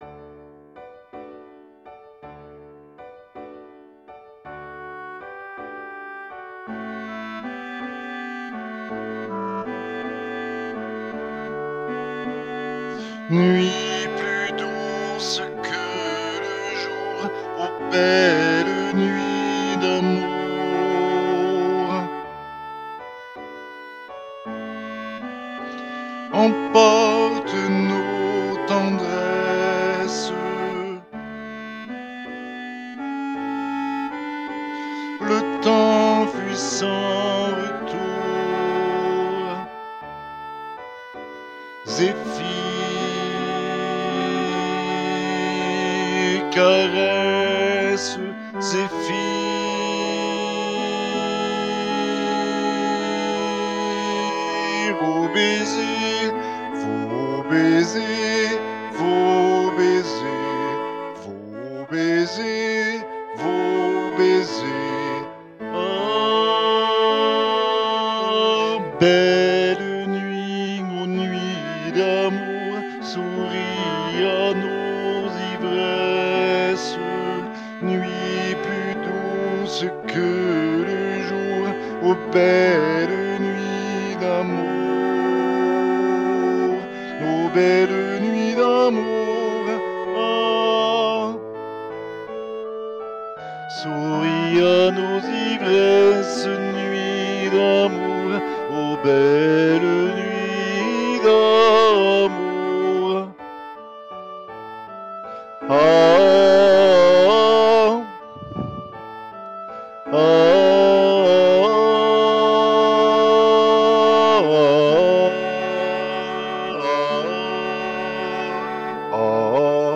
voix chantée
Alti-Barcarolle-des-contes-dHoffmann.mp3